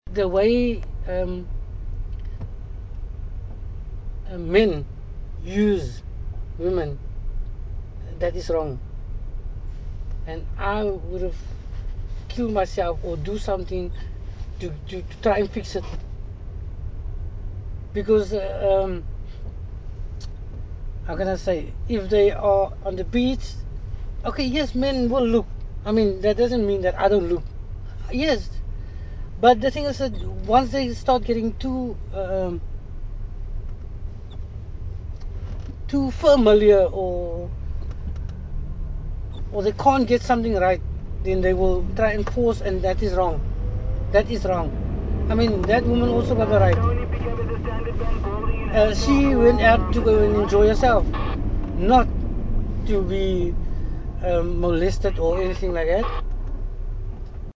Taxi tales - Cape Town taxi drivers speak about feminism
During their stay, they spoke with taxi drivers about feminism, women in politics and violence against women.